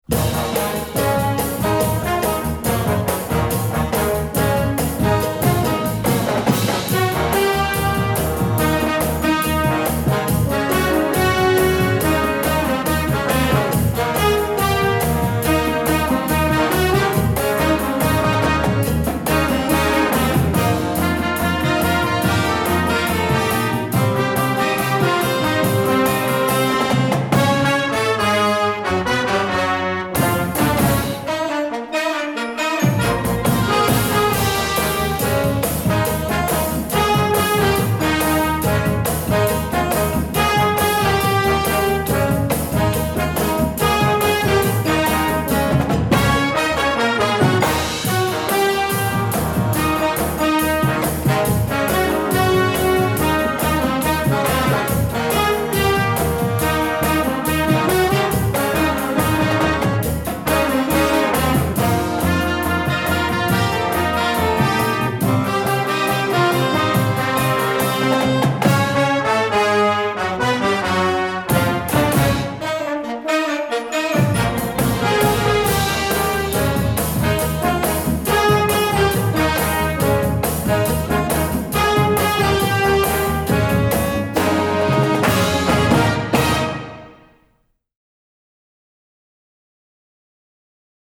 Gattung: Moderner Einzeltitel
Besetzung: Blasorchester
ein treibender Rocksong in einer angesagten neuen Hitparade!